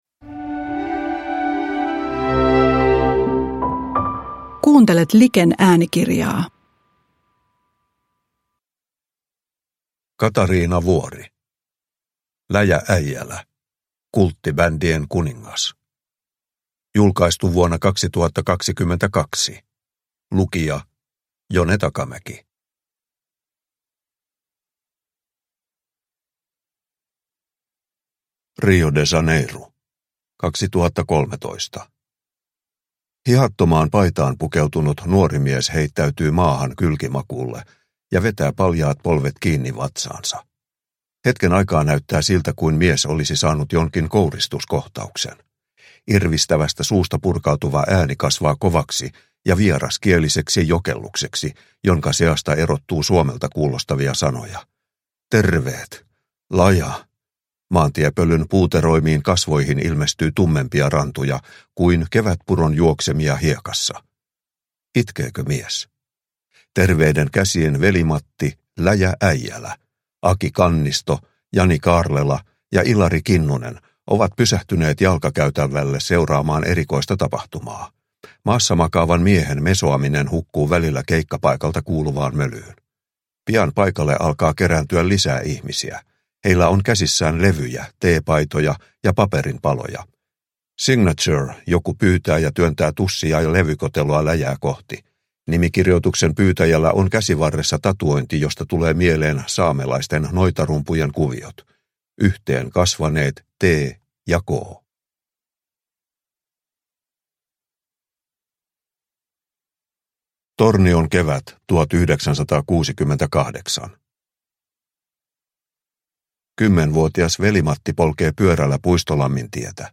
Läjä Äijälä – Ljudbok – Laddas ner